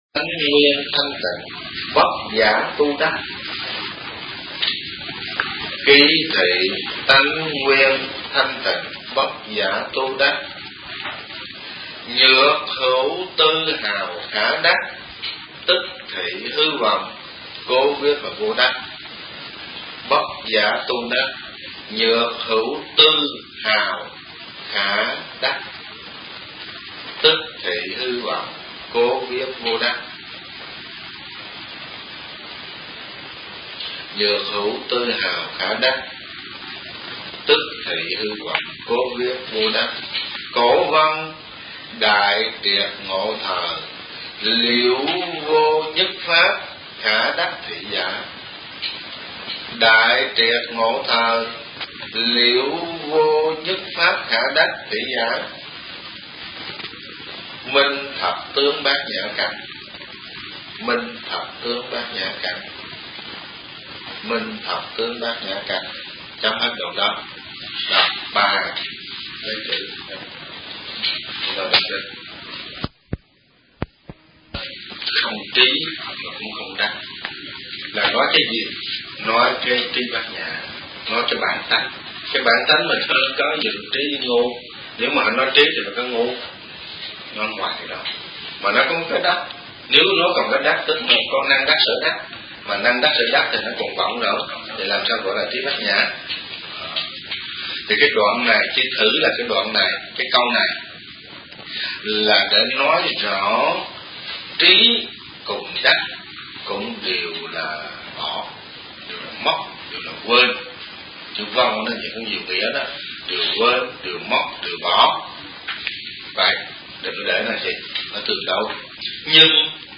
Kinh Giảng Nhị Khóa Hiệp Giải